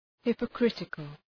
Προφορά
{,hıpə’krıtıkəl} (Επίθετο) ● υποκριτικός